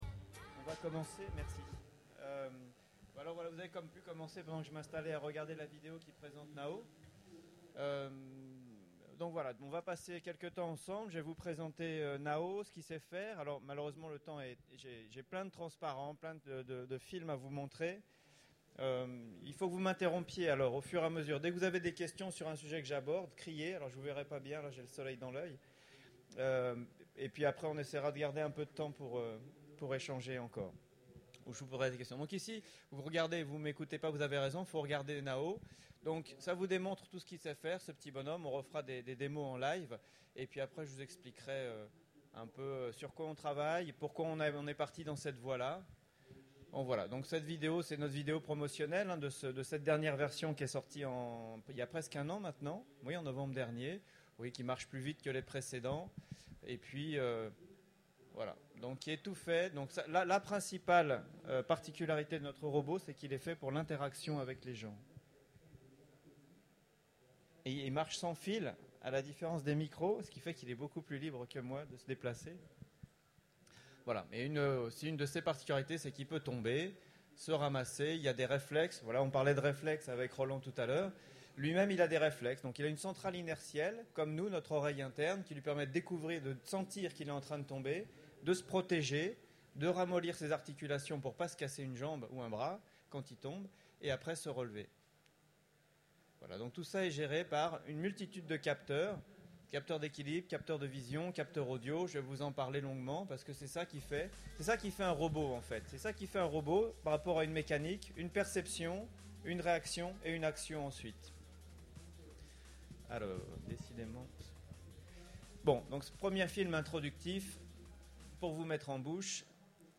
Utopiales 12 : Conférence Rencontre avec le robot Nao